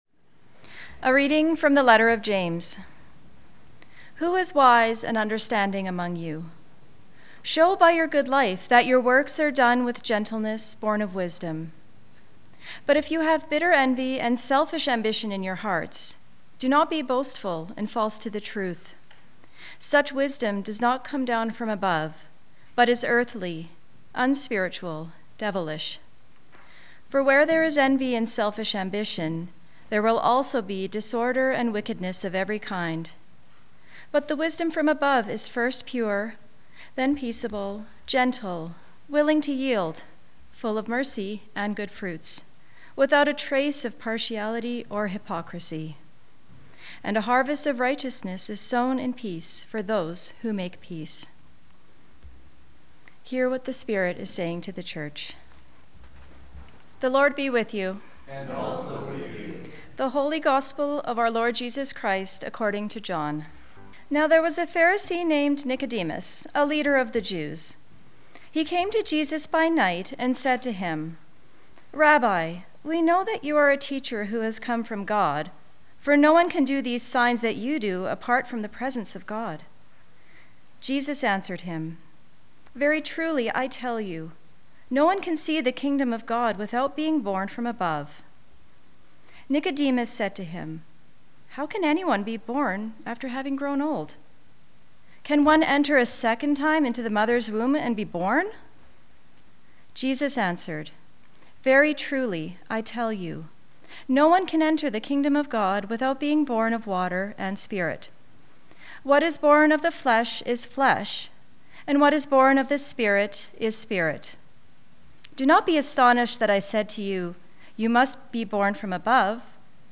Sermons | The Church of the Good Shepherd